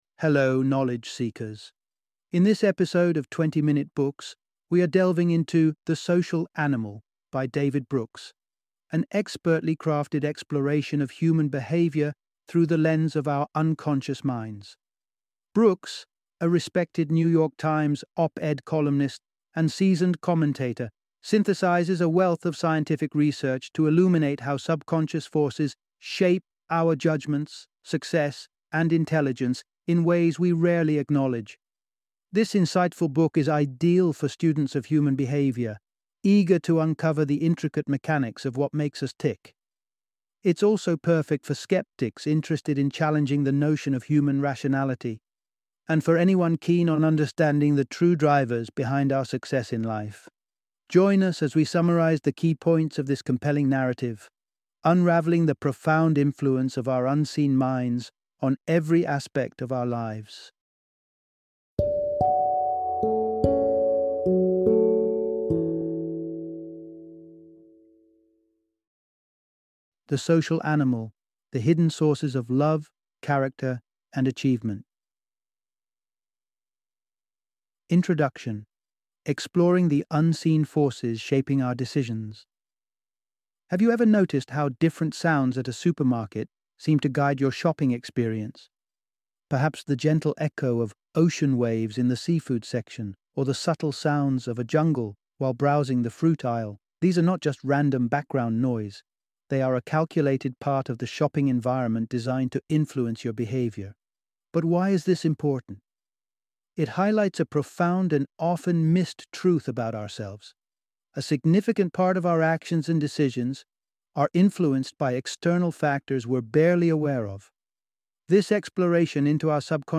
The Social Animal - Audiobook Summary